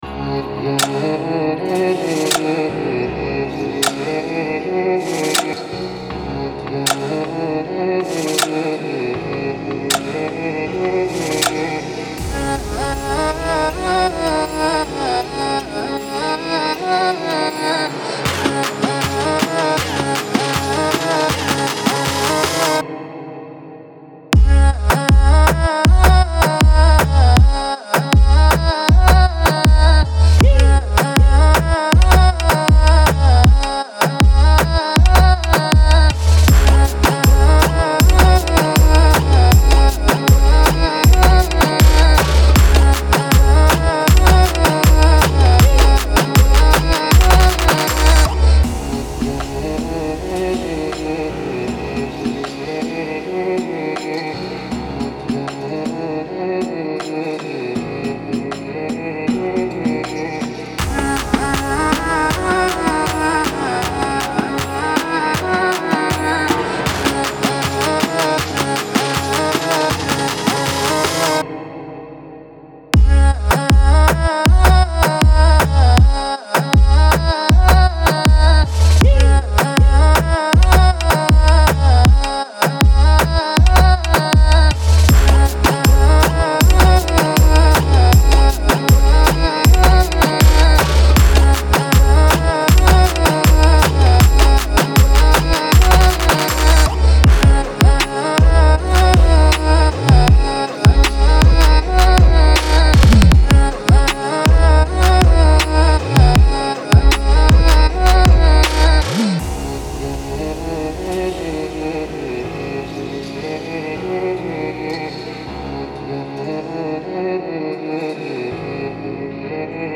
это энергичная трек в жанре хип-хоп